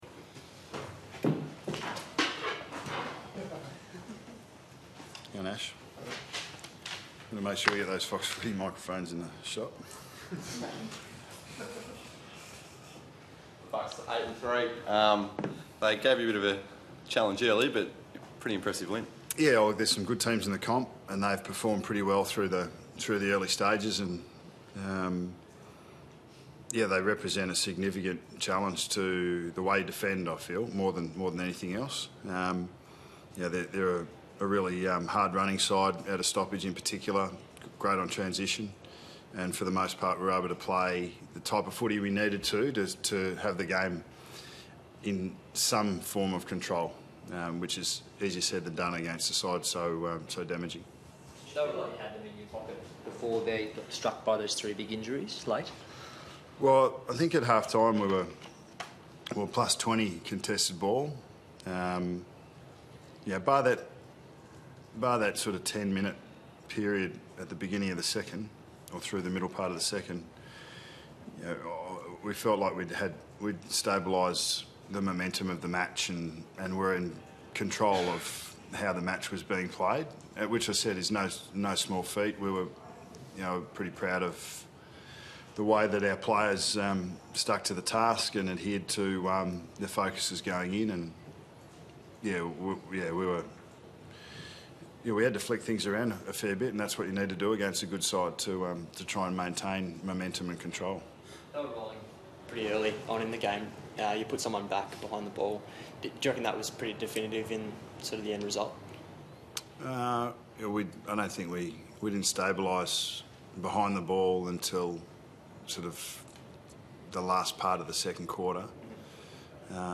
Listen to coach Nathan Buckley take questions from the media following Collingwood's 42-point win over Greater Western Sydney in round 11.